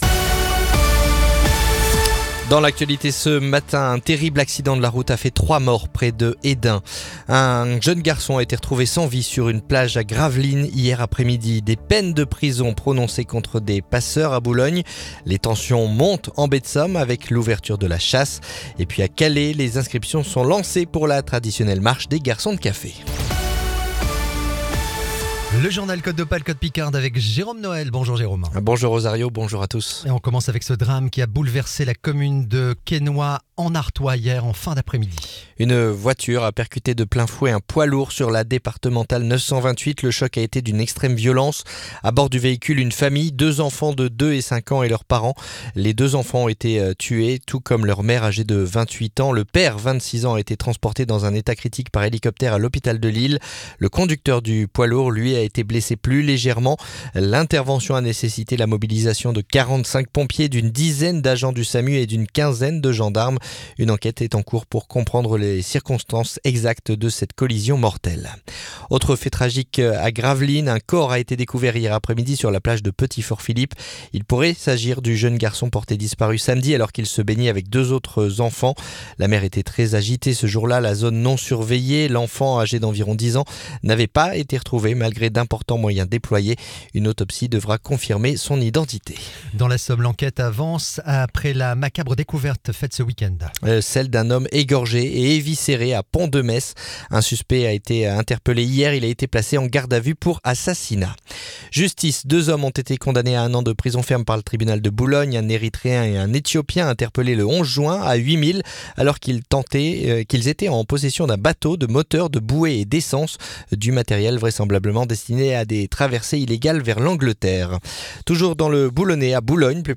Le journal du mercredi 6 août